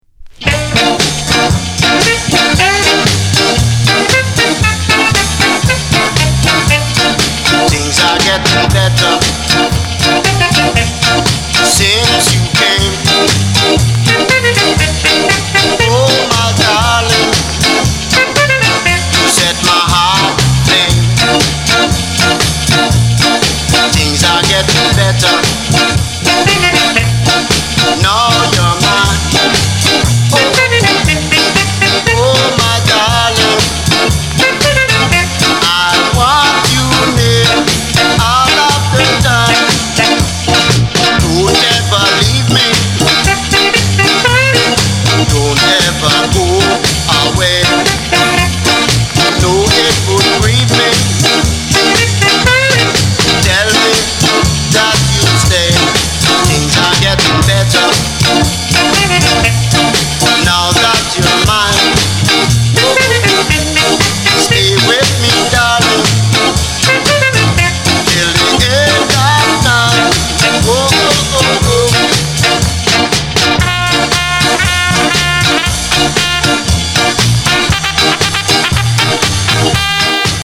Genre:  Ska